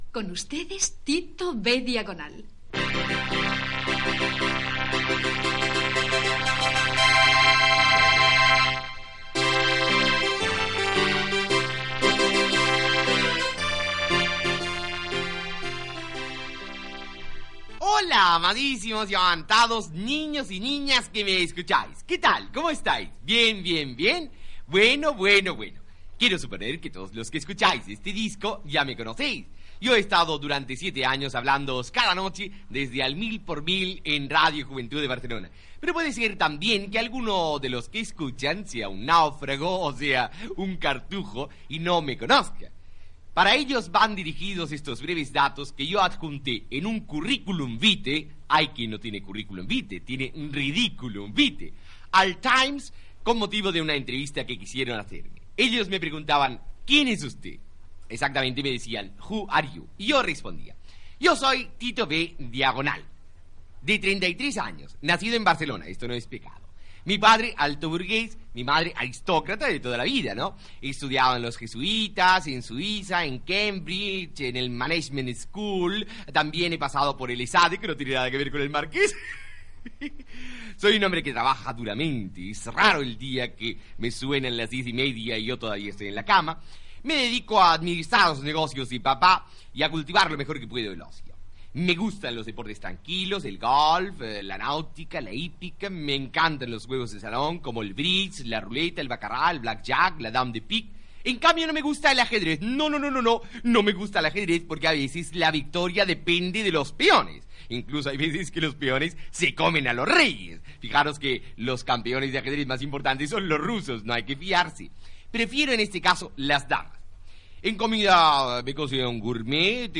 Entreteniment
El disc conté 7 monòlegs.